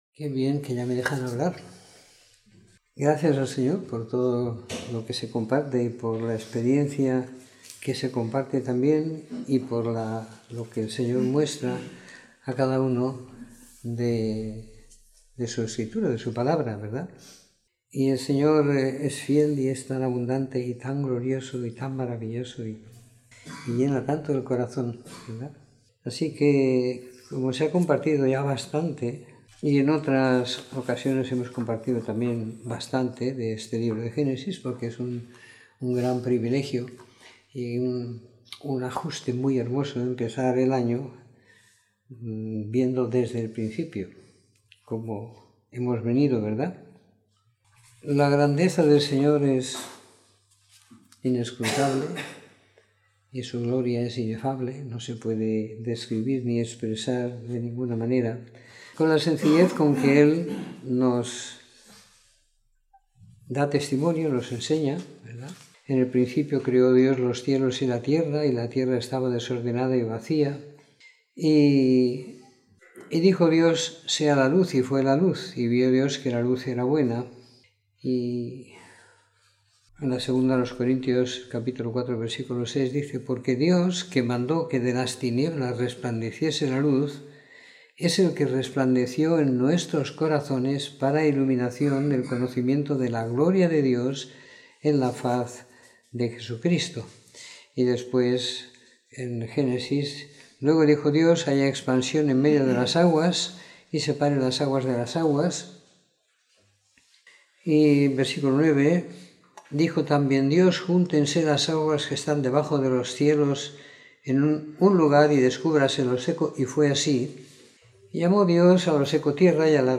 Comentario en Génesis 1-3 - 05 de Enero de 2018
Comentario en el libro de Génesis capítulos del 1 al 3 siguiendo la lectura programada para cada semana del año que tenemos en la congregación en Sant Pere de Ribes.